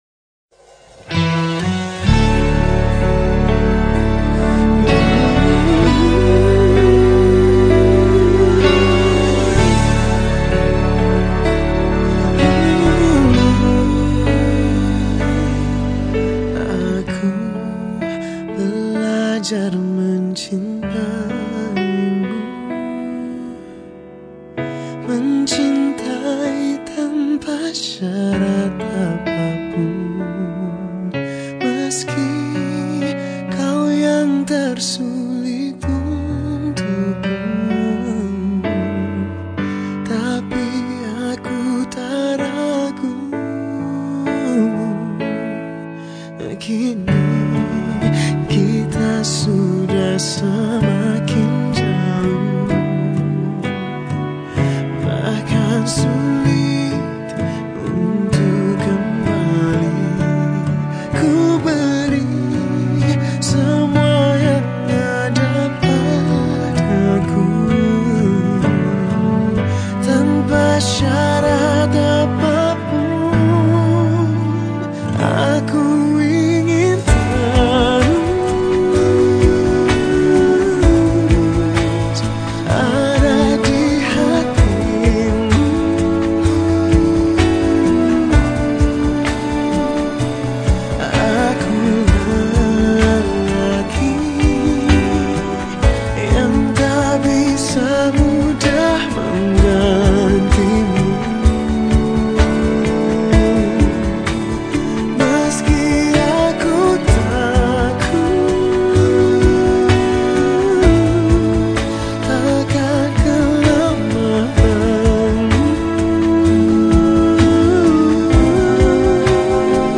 Genre Musik                                 : Musik Pop
Instrumen                                      : Vokal